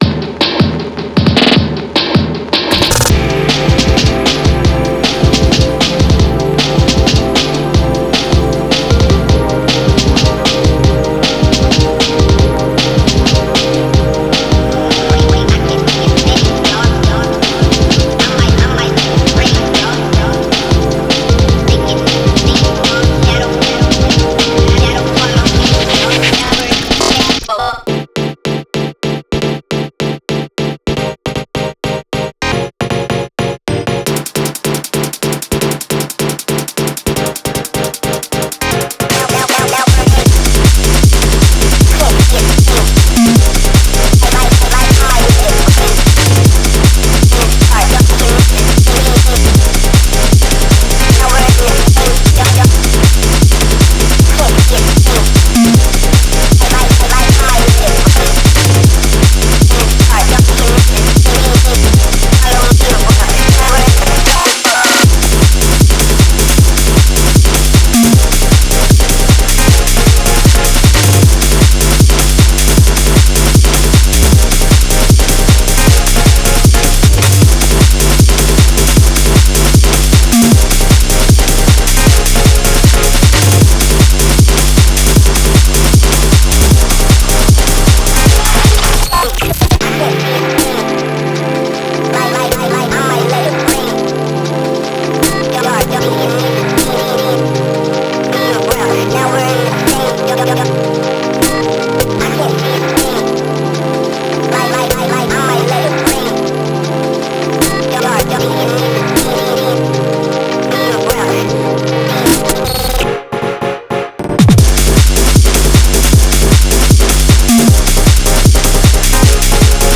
a bouncy digital hardcore song with some very glitchy